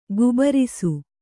♪ gubarisu